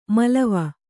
♪ malava